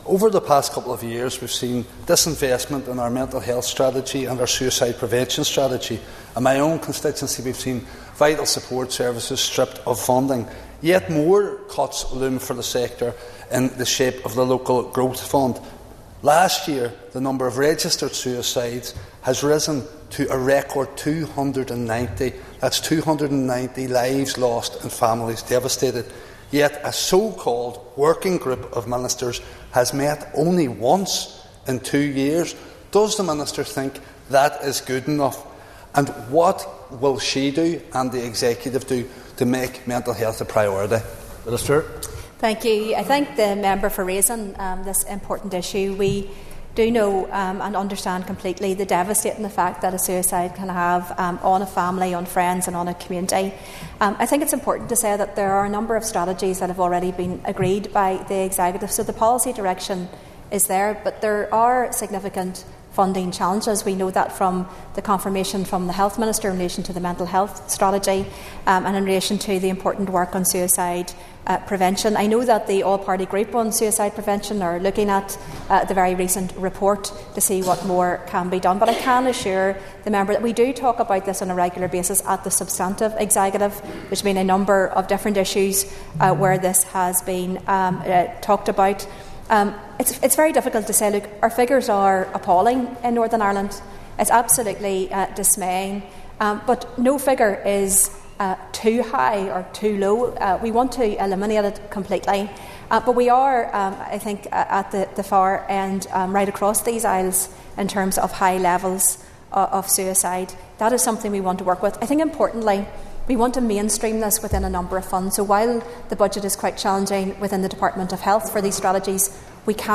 Emma Little Pengeley was responding in the Assembly to Foyle MLA Mark Durkan, who said figures in Northern Ireland are disproportionately high compared to other areas in the islands.